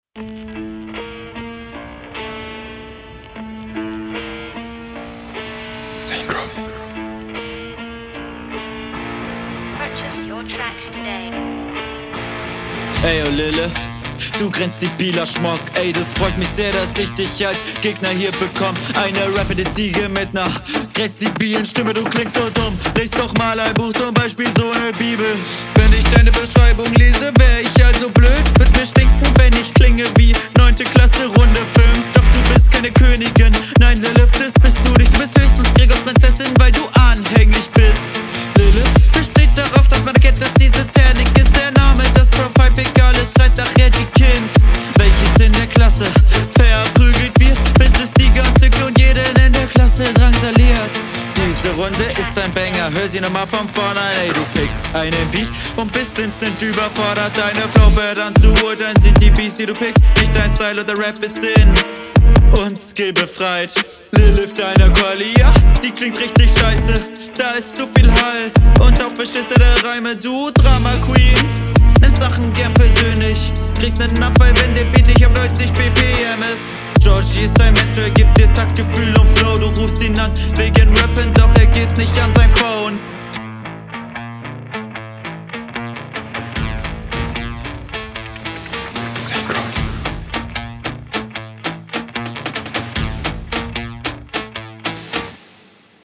beat ist geil, feier ich den bezug zum gegner check ich nicht so ganz mit …